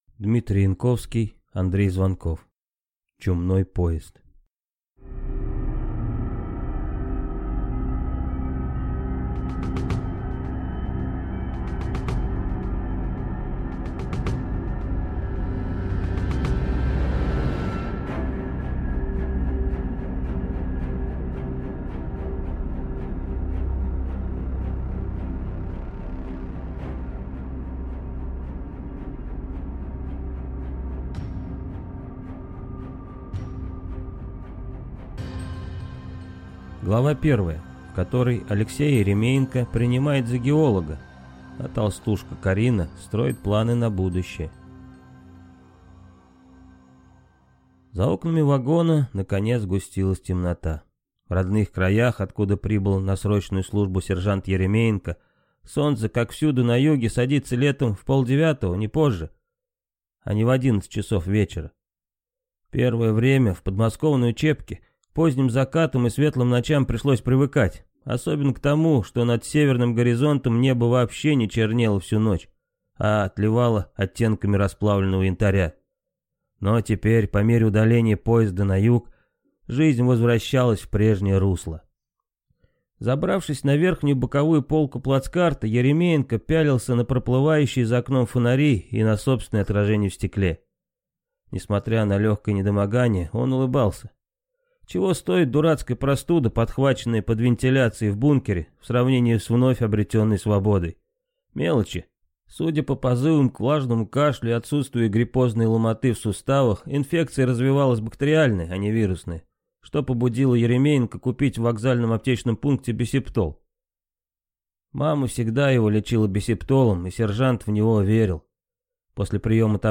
Аудиокнига Чумной поезд | Библиотека аудиокниг
Прослушать и бесплатно скачать фрагмент аудиокниги